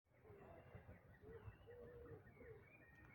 Eigentlich wollte ich einen anderen Vogel aufnehmen, aber dann zu Hause, als ich mir die Aufnahmen anhörte, viel mir auf, dass da ja ein Grünspecht gesungen hatte.
Grünspecht in Berlin
Allerdings ist die Aufnahmequalität nicht besonders gut und ich war dankbar, dass ich auf iNaturalist Rückmeldungen zur Bestimmung bekam. Denn so klar war meine Aufnahme nicht.